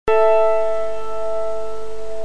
Her er et klaver c (440 Hz) og klaver
Prøv, om du kan høre at i klaverets c, vokser 1 overtone (880 Hz) op og forsvinder igen.
Eksemplerne målt med Datalyse og gemt som wavefiler.
Klaverk.mp3